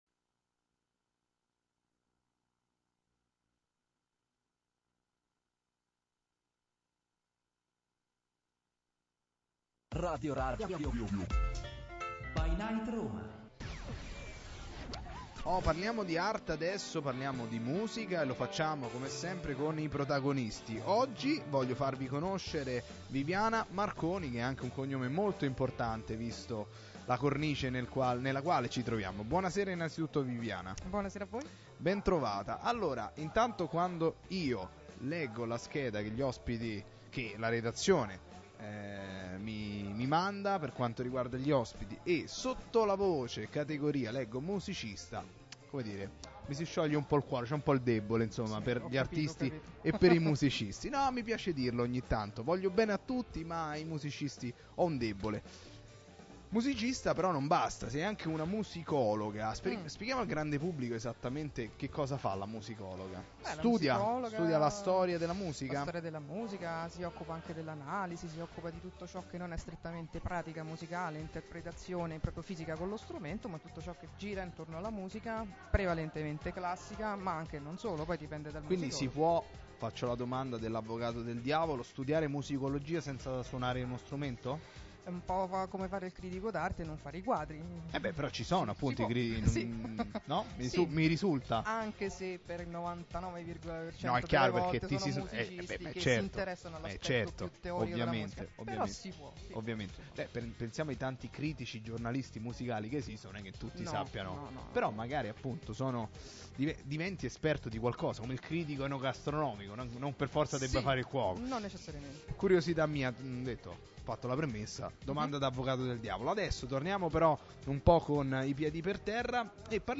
Intervista 17.08.2017